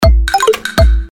На ксилофоне